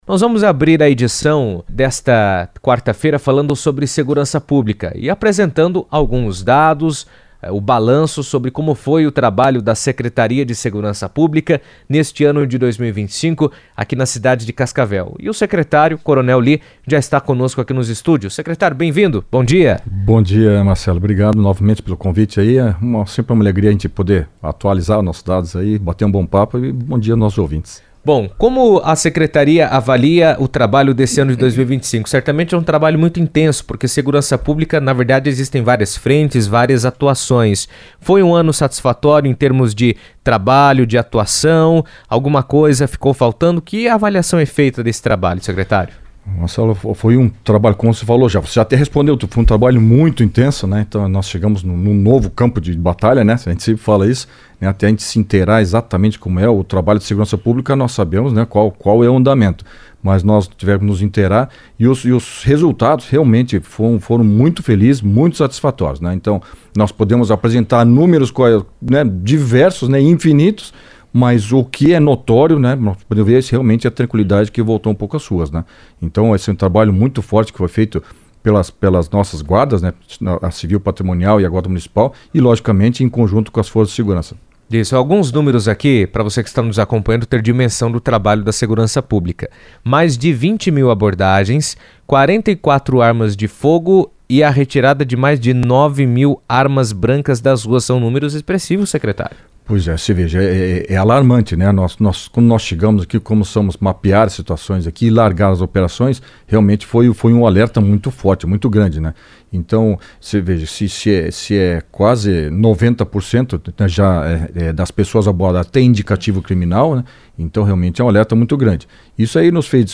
Em 2025, a segurança pública de Cascavel intensificou suas ações no combate ao crime, realizando mais de 20 mil abordagens e retirando das ruas 44 armas de fogo e mais de 9 mil armas brancas. O trabalho conjunto da Guarda Municipal e da Guarda Civil Patrimonial também atendeu a mais de 10 mil ocorrências e resultou na recuperação de 142 veículos. Coronel Lee, secretário de Segurança Pública e Proteção à Comunidade de Cascavel, comentou sobre o tema em entrevista à CBN, destacando a importância da atuação integrada para garantir a segurança da população.